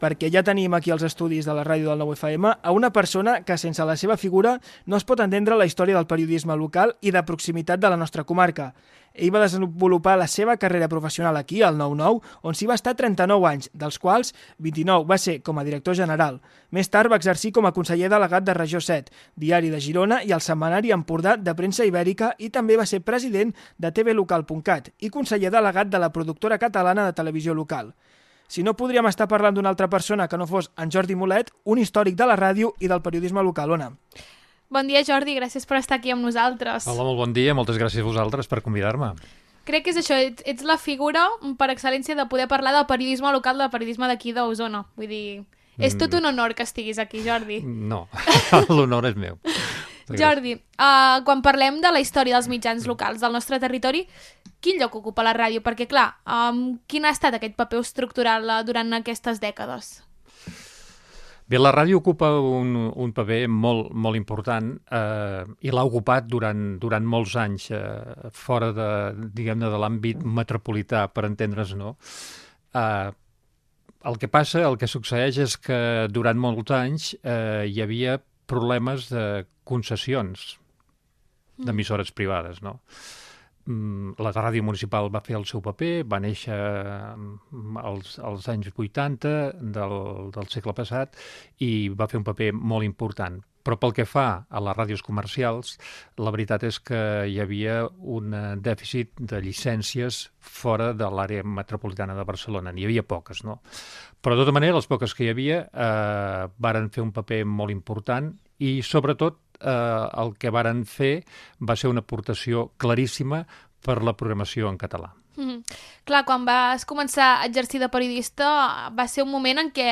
Fragment d'una entrevista
Entreteniment